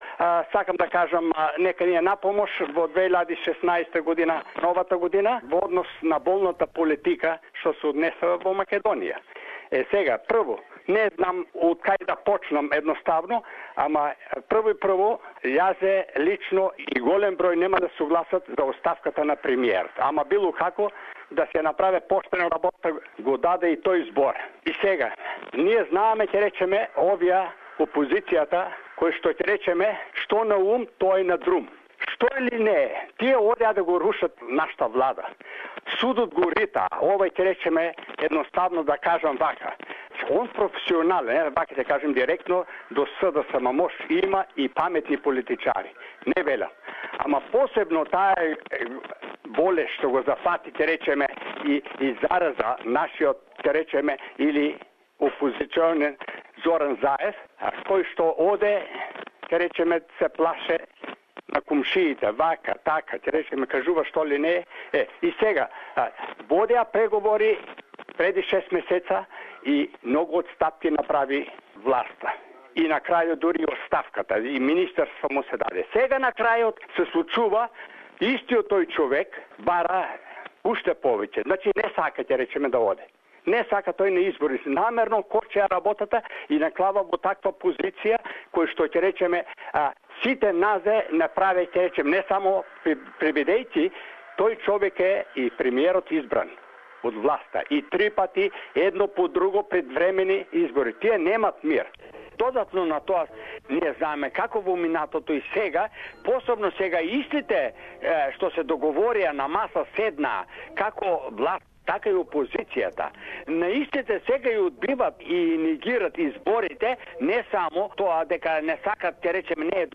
Voxpop